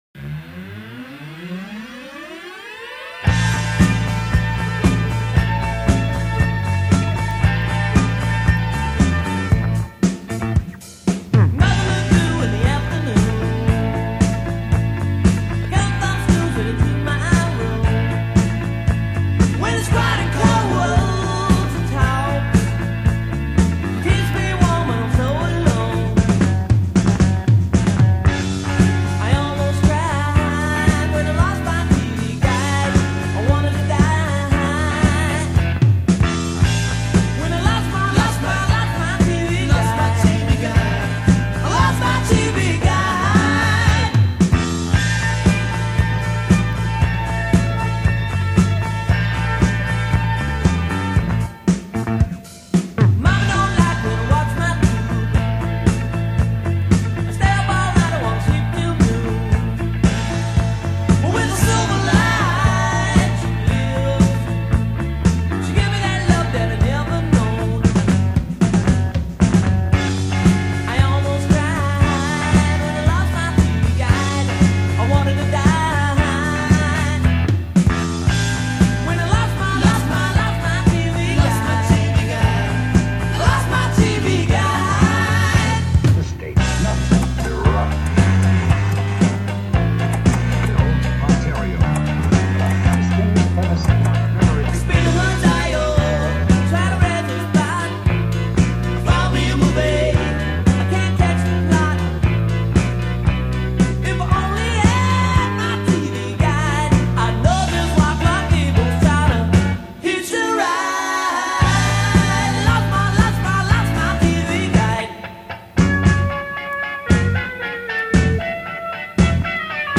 Tracks 4-6 Recorded at Cherokee Studios
Sax and Keyboards.
Drums.
Lead Guitar, Synth, Voice.
Lead Vocal and Rhythm Guitar.